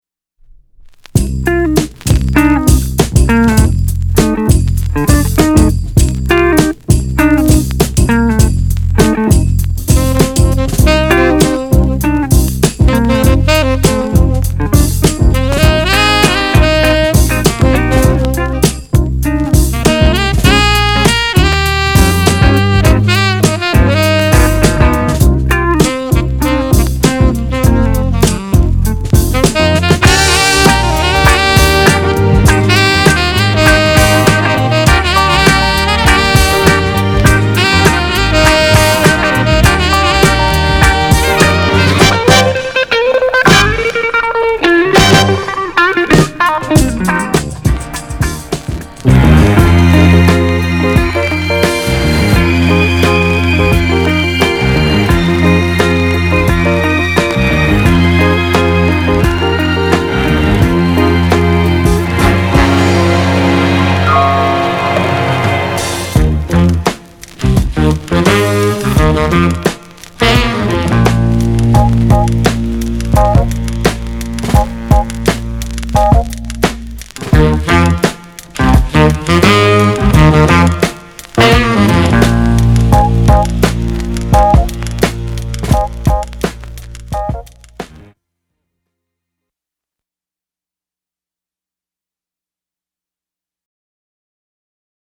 category Fusion & New Age